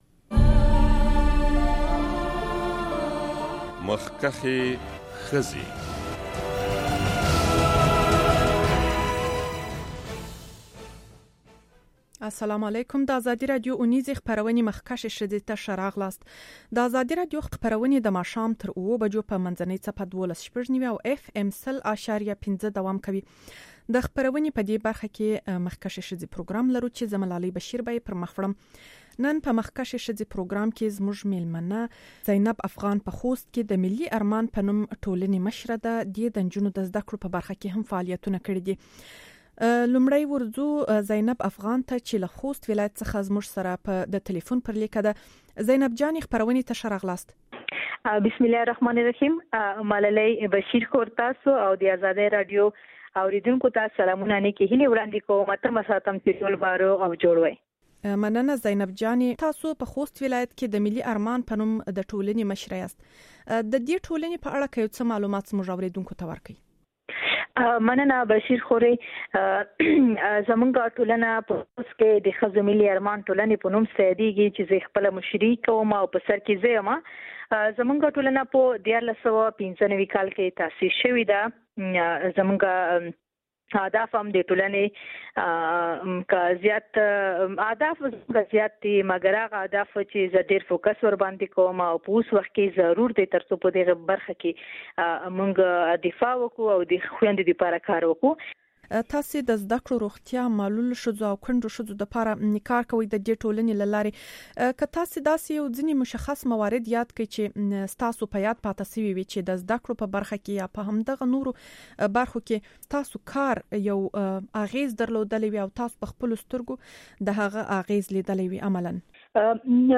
د مخکښې ښځې پروګرام په دې برخه کې دوه مېلمنې لرو.